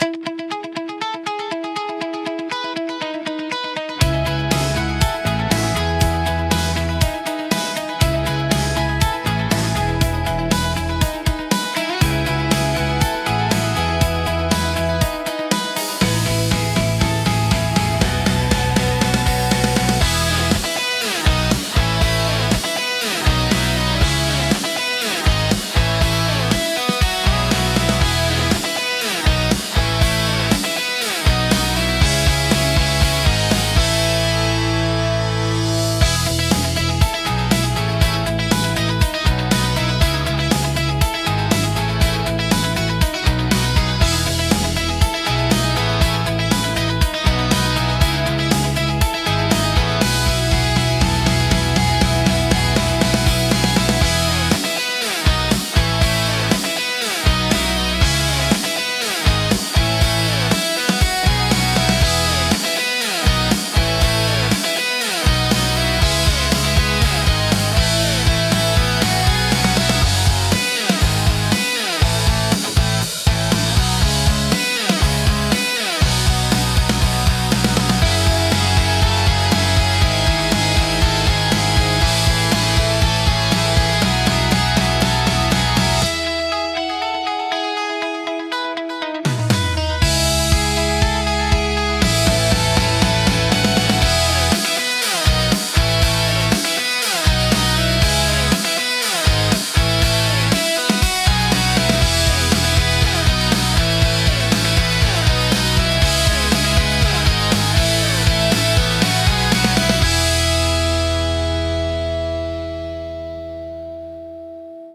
明るく親しみやすい曲調で幅広い層に支持されています。